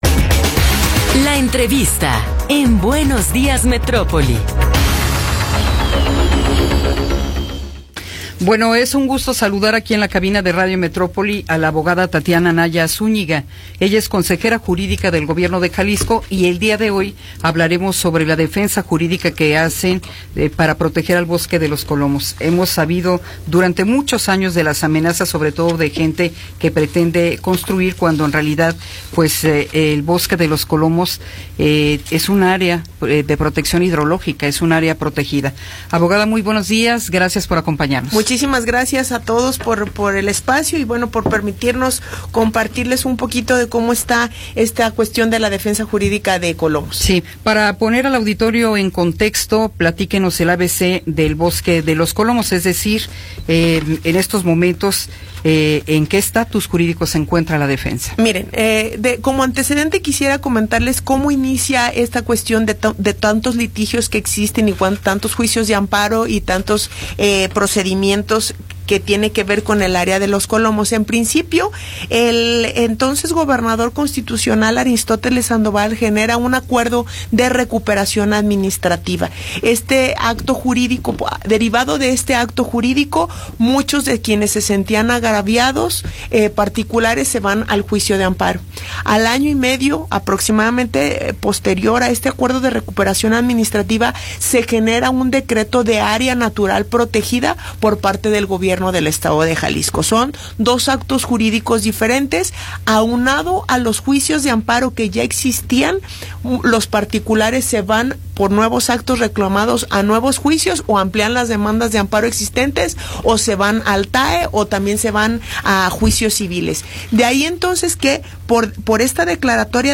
Entrevista con Tatiana Anaya Zúñiga